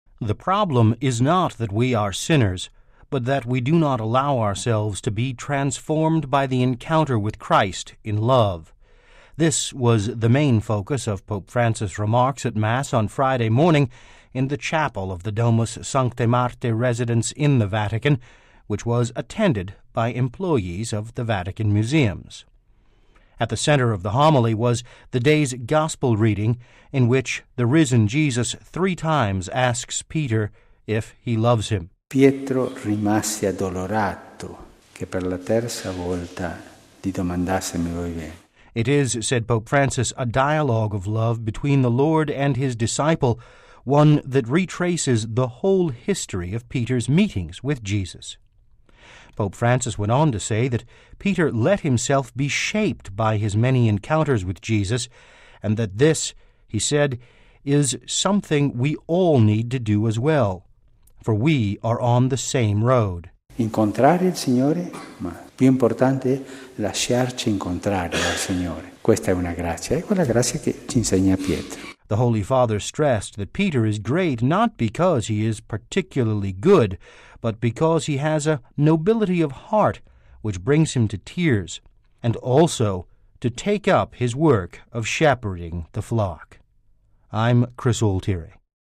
(Vatican Radio) The problem is not that we are sinners, but that we do not allow ourselves to be transformed by the encounter with Christ in love: this was the main focus of Pope Francis’ remarks at Mass on Friday morning in the chapel of the Domus Sanctae Marthae residence in the Vatican, which was attended by employees of the Vatican Museums.